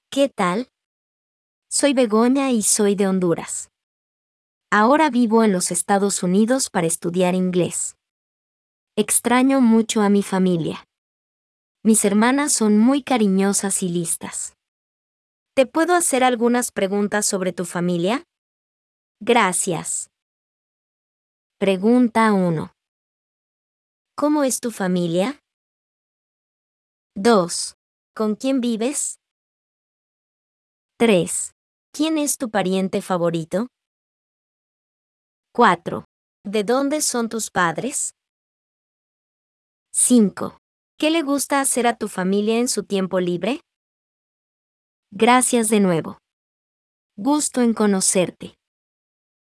Begoña has questions for you about your family.